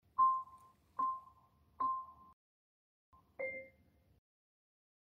mic_grab_count_down.mp3